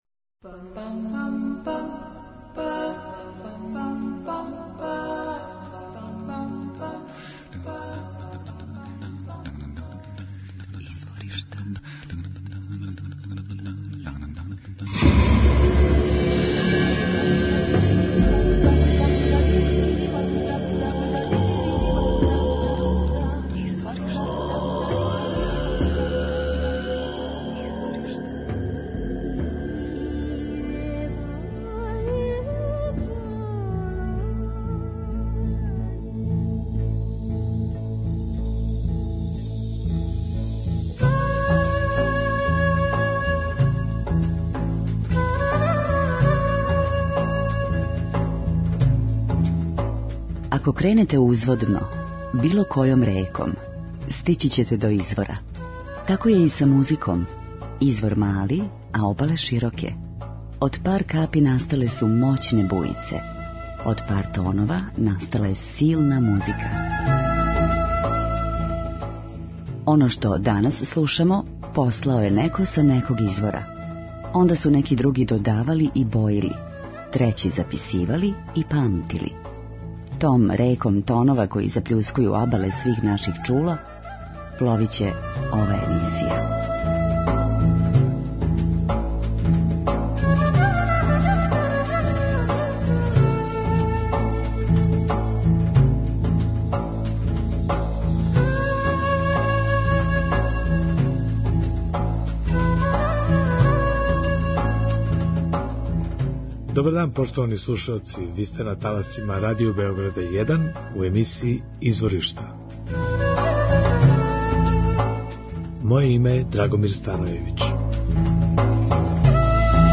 Емисија посвећена Светској музици православних народа. Слушаћемо извођаче и музику Србије, Македоније, Грчке, Румуније, Бугарске, Кипра и Русије.
Слушаћемо извођаче и музику Србије, Македоније, Грчке, Румуније, Бугарске, Кипра и Русије. преузми : 9.79 MB Изворишта Autor: Музичка редакција Првог програма Радио Београда Музика удаљених крајева планете, модерна извођења традиционалних мелодија и песама, културна баштина најмузикалнијих народа света, врели ритмови... У две речи: World Music.